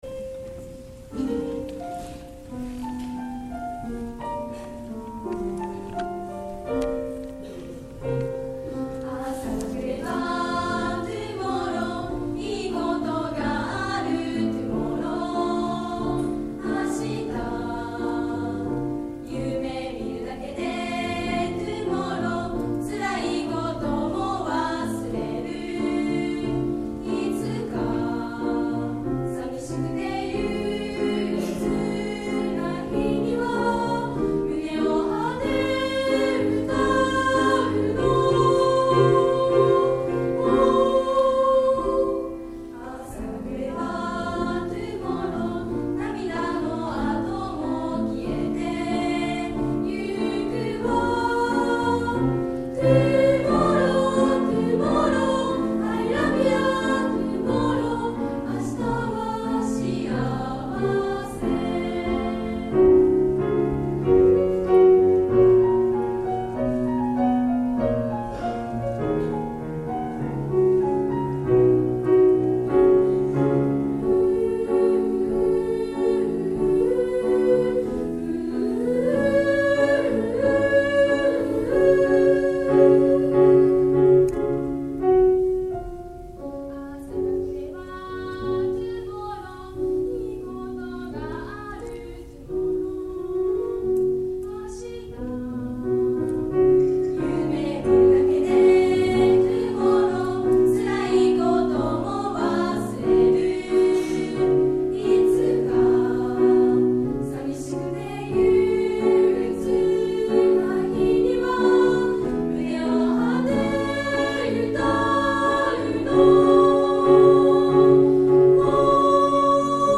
3月時間割表 弥生のひと時、音楽部の声をお楽しみください。 音楽部「Tomorrow」